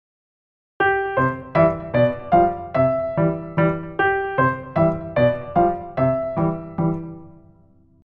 気づいたら同じ和音をずっと弾いていたというありがちな失敗例を紹介します。
全く同じモチーフを２回繰り返すとこうなります。